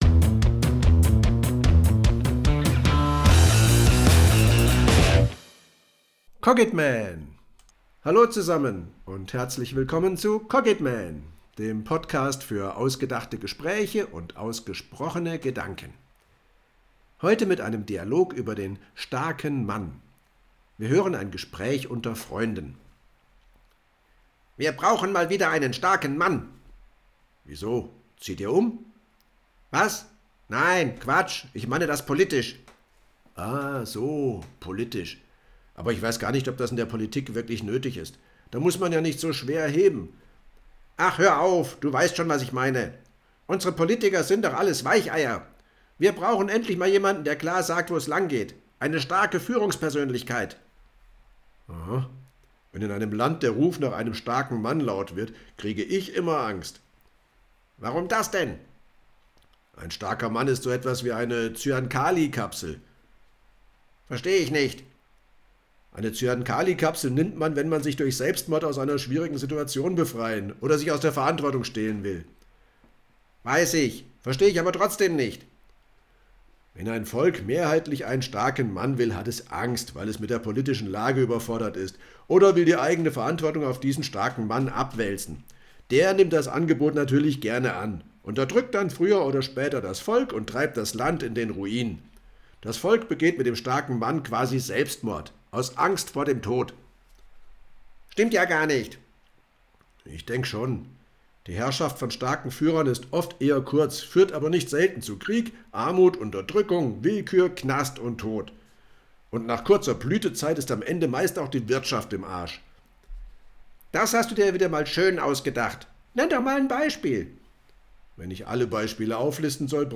Dialog_Der-starke-Mann.mp3